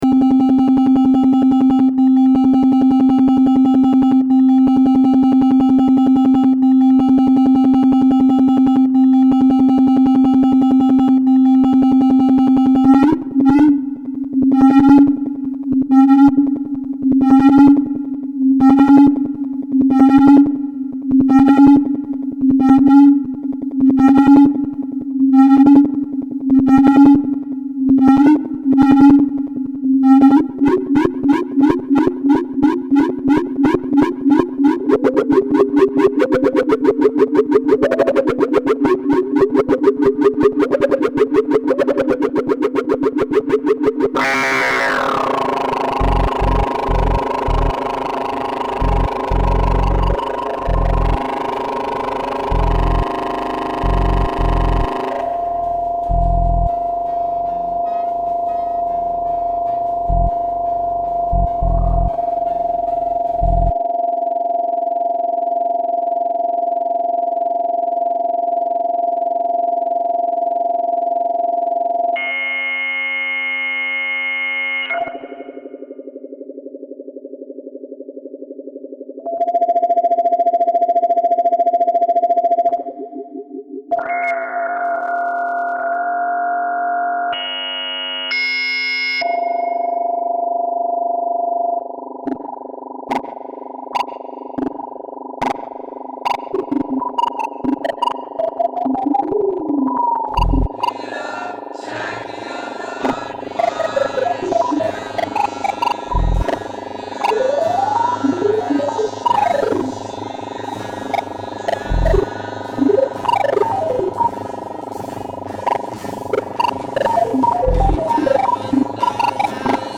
Filled under: Sounding
Test = Rough Cuts of Naked sparkles [intensity - cars - fast drive - tunnels].
Laughter.
Reverb.